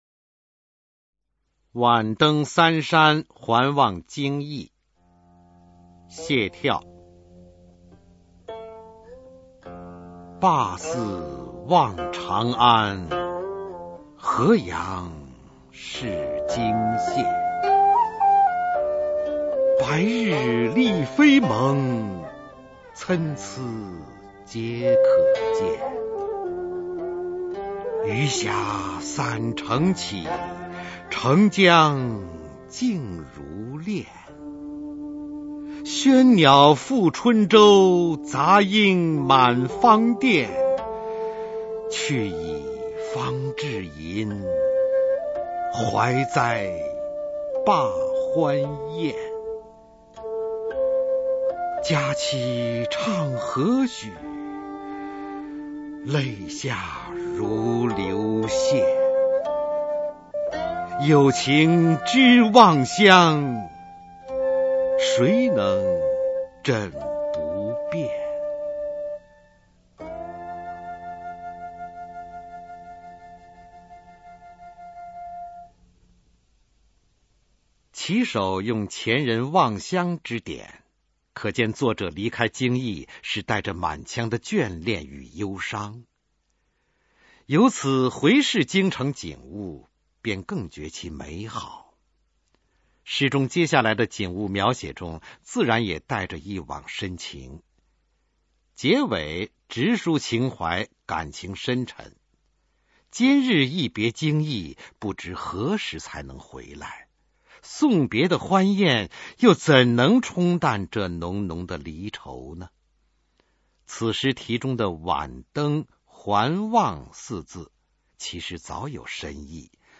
首页 视听 语文教材文言诗文翻译与朗诵 高中语文必修一